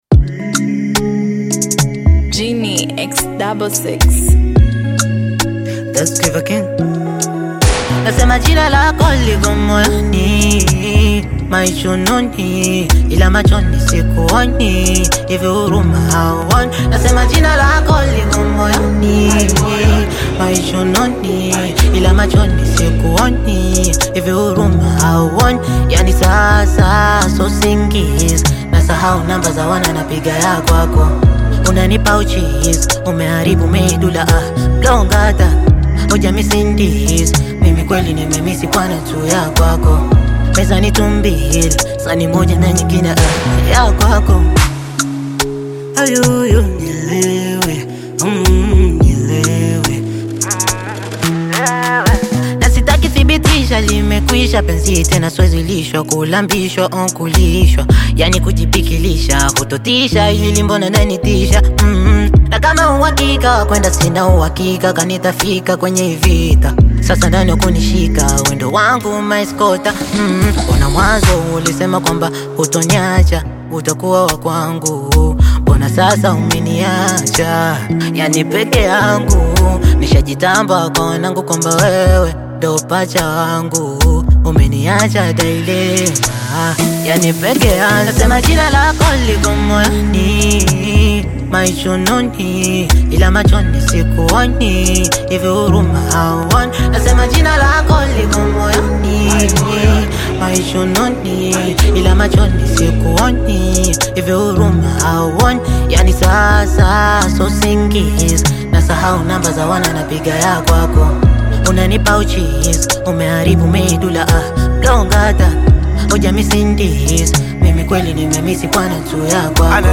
lively Afro-Beat single
Genre: Bongo Flava